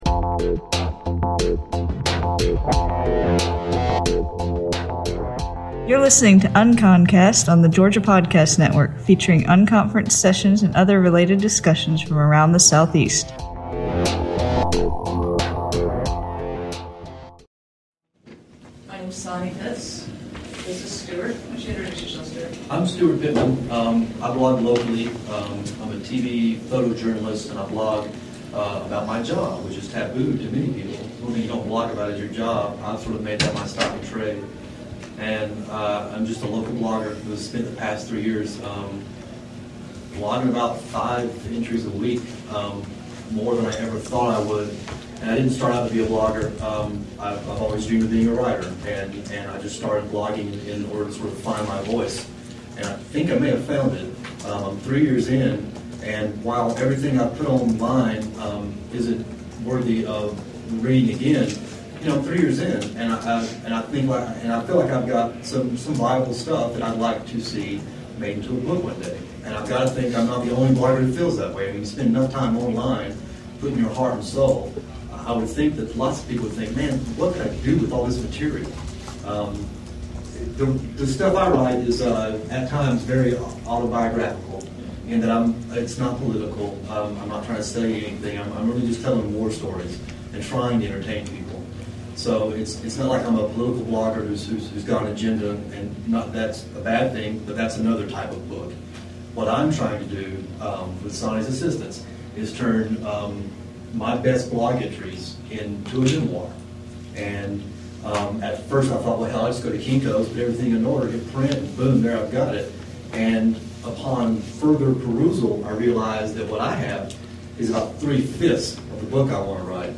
Featuring unconference sessions and other related discussions from around the Southeast.